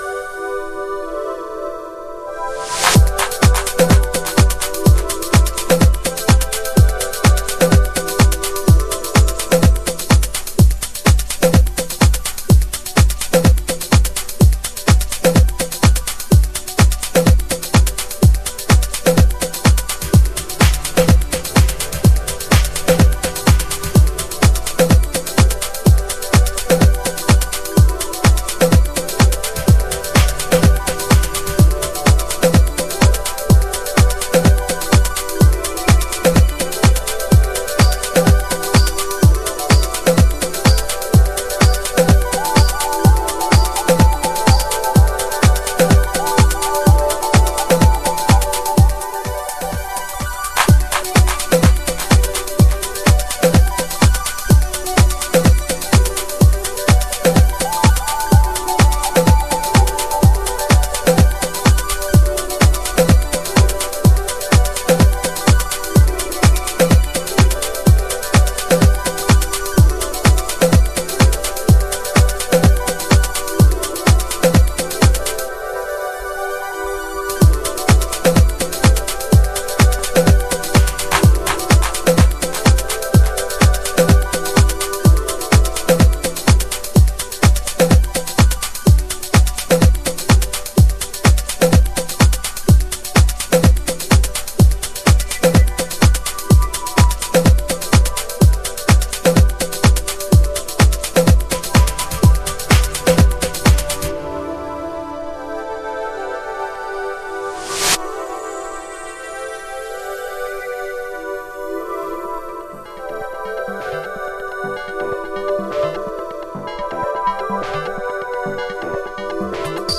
House / Techno
シンセでトロピカルを表現した陽性ハウス名作。